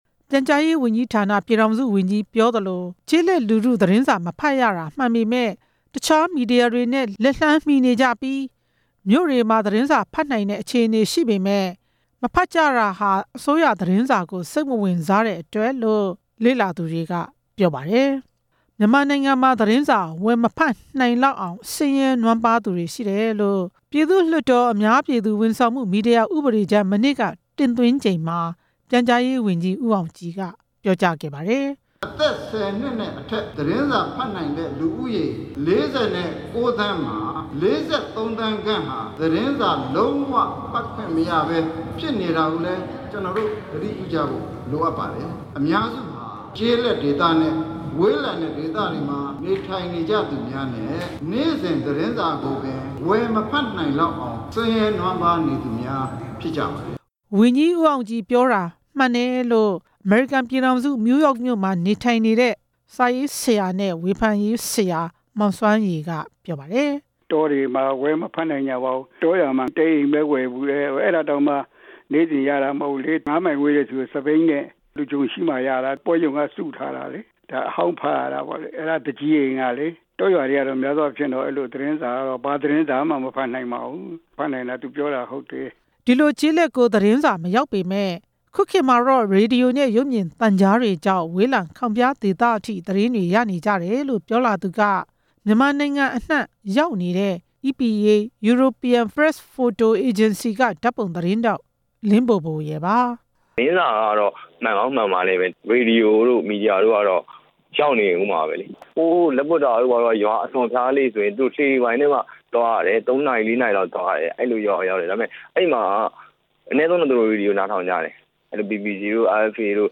နေပြည်တော်မှာ ဒီကနေ့ ကျင်းပခဲ့တဲ့ ပြည်သူ လွှတ်တော်မှာ ပြန်ကြားရေးဝန်ကြီးဋ္ဌာနရဲ့ အများပြည်သူ ဝန်ဆောင်မှု မီဒီယာဥပဒေကြမ်း တင်သွင်းချိန်မှာ ပြန်ကြားရေး ပြည်ထောင်စုဝန်ကြီး ဦးအောင်ကြည်က ထည့်သွင်း ပြောကြားခဲ့တာပါ။